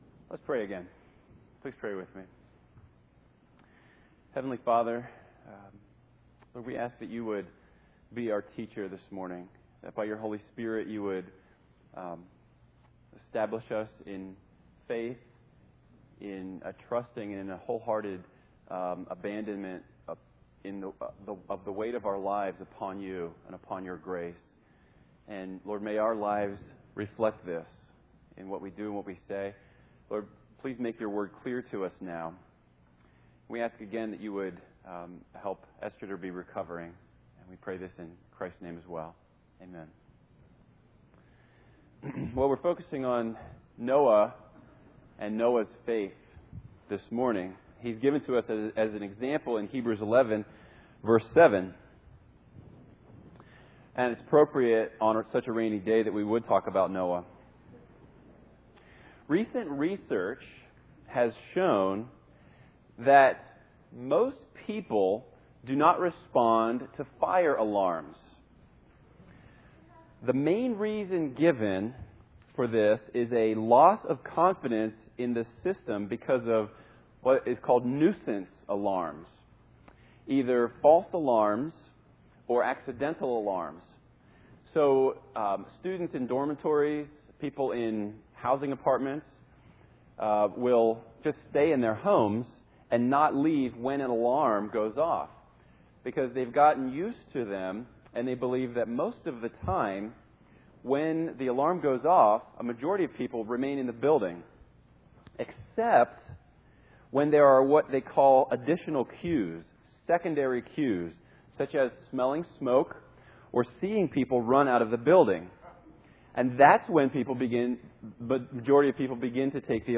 preaching on Hebrews 11:1-7